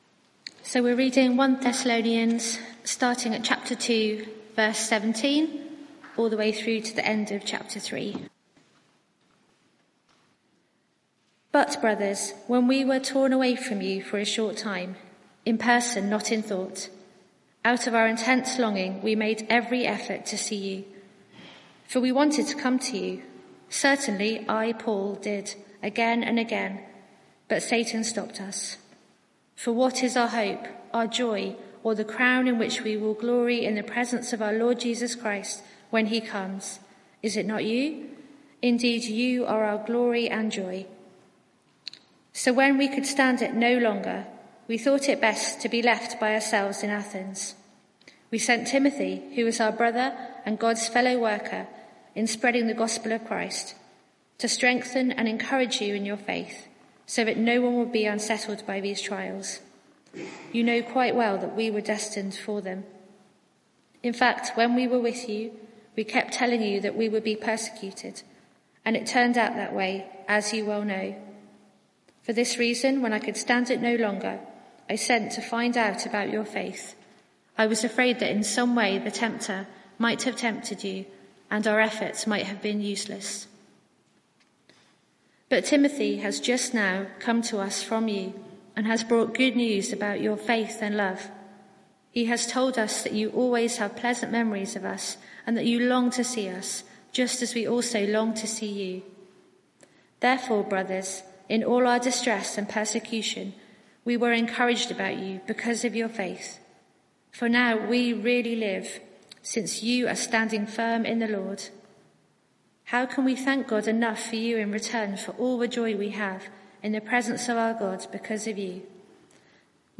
Media for 6:30pm Service on Sun 13th Aug 2023 18:30
Sermon (audio)